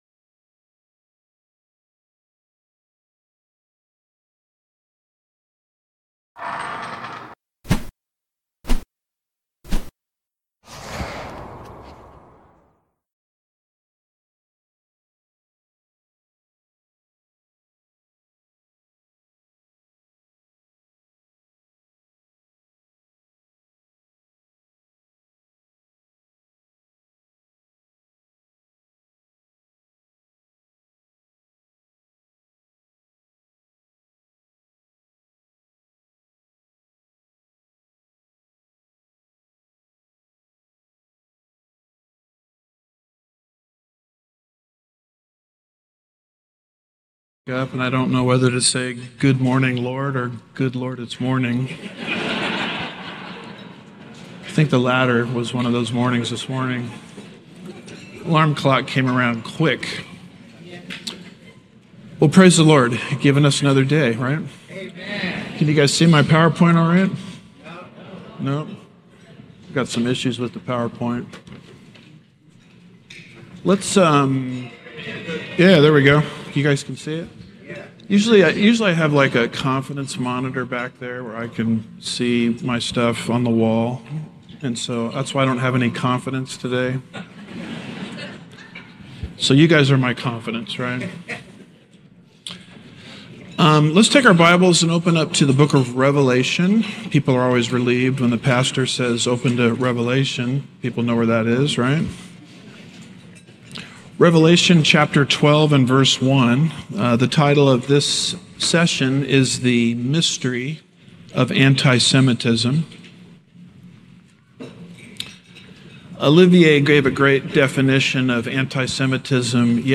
Sermons
2026 Prophecy Conference